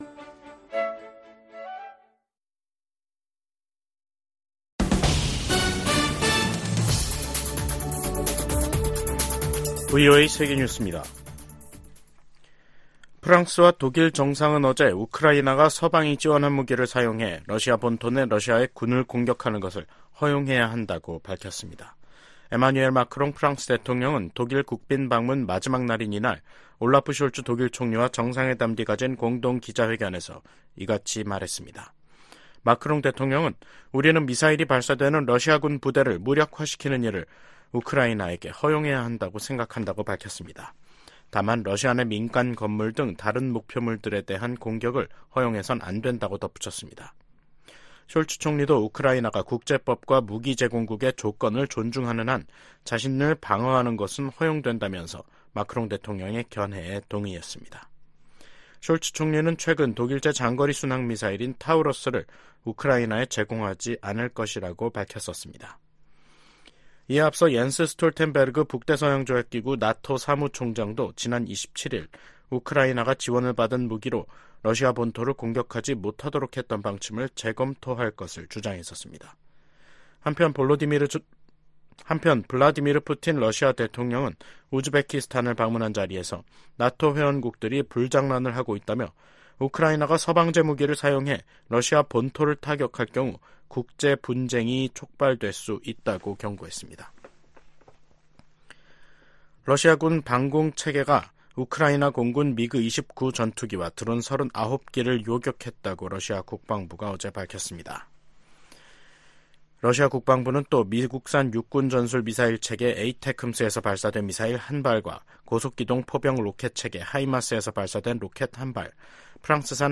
세계 뉴스와 함께 미국의 모든 것을 소개하는 '생방송 여기는 워싱턴입니다', 2024년 5월 29일 저녁 방송입니다. '지구촌 오늘'에서는 이스라엘군의 팔레스타인 라파 난민촌 공격이 조 바이든 행정부가 언급한 ‘넘지 말아야 할 선(레드라인)’을 넘은 것은 아니라고 미국 정부 관리들이 밝힌 소식 전해드리고, '아메리카 나우'에서는 도널드 트럼프 전 대통령의 성추문 입막음 의혹 재판의 최후변론이 종결된 이야기 살펴보겠습니다.